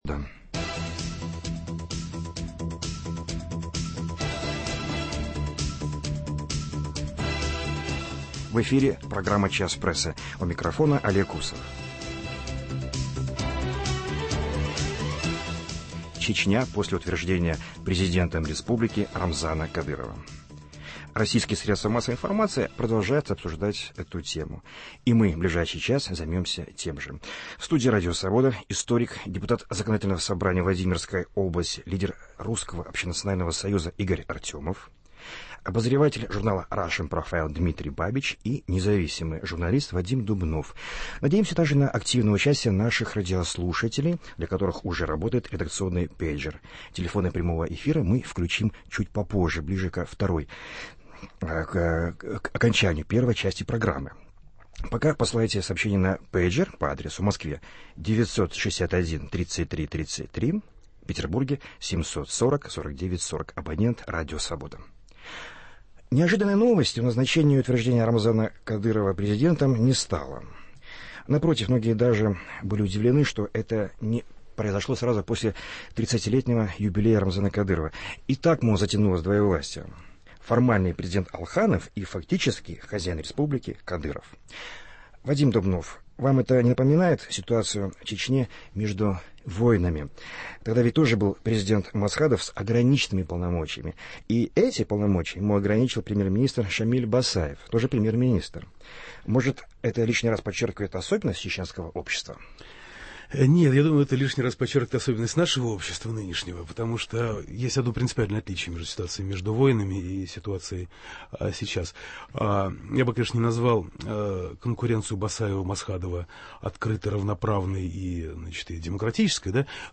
Участники передачи: историк, депутат Законодательного собрания Владимирской области Игорь Артёмов